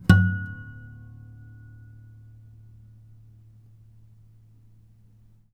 harmonic-07.wav